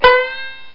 Banjo Bend Sound Effect
Download a high-quality banjo bend sound effect.
banjo-bend.mp3